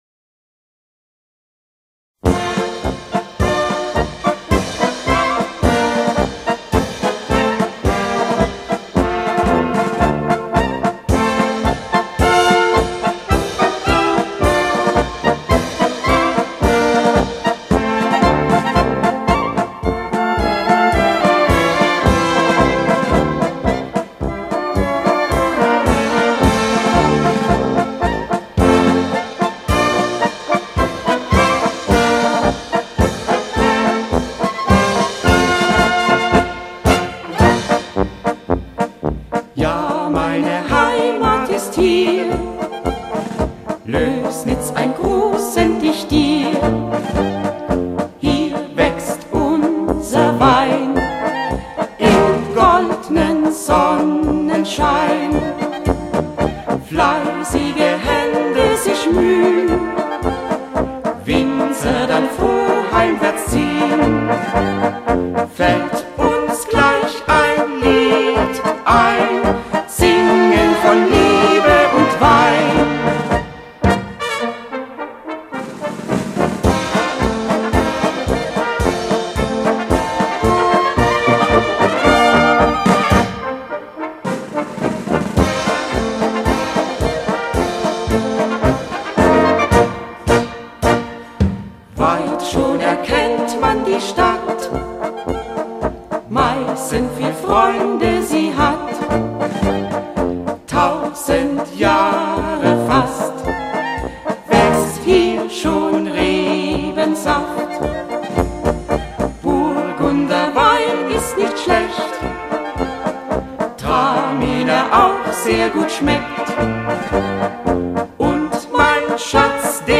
Polka mit Gesang GEMA-Werk.-Nr